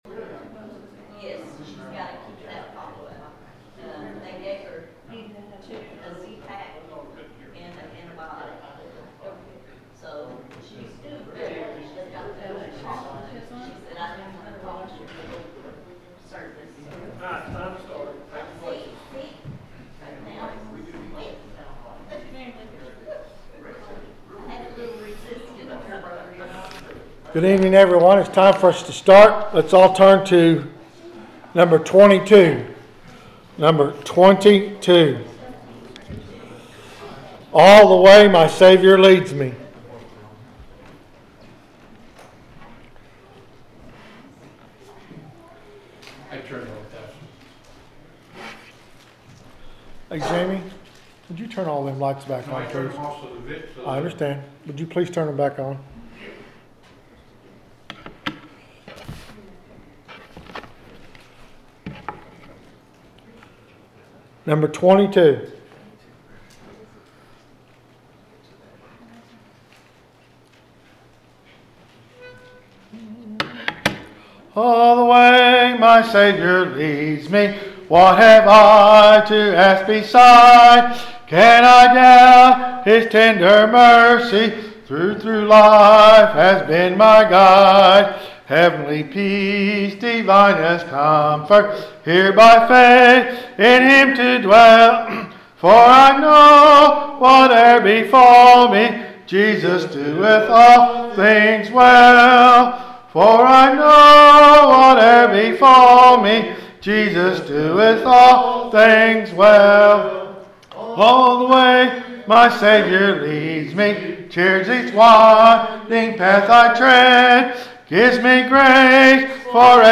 The sermon is from our live stream on 11/2/2025